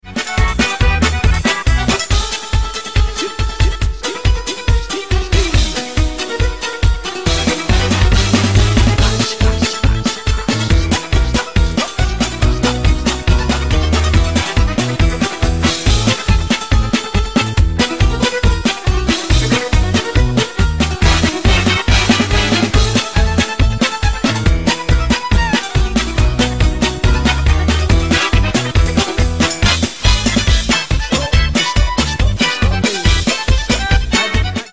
зажигательные
веселые
инструментальные
Народные
цыганские
Polka
Веселая и зажигательная музыка